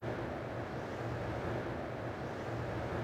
tunnelLeft.wav